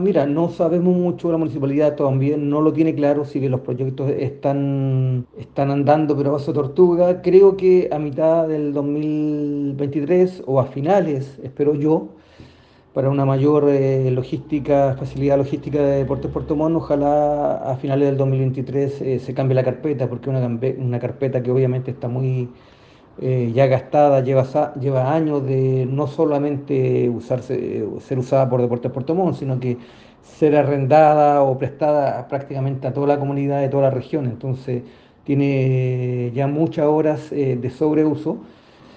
El club Deportes Puerto Montt terminó la temporada en términos financieros con un déficit de $250 millones, situación que hará compleja la conformación del plantel 2023. En entrevista con el programa Onda Deportiva de radio Sago Puerto Montt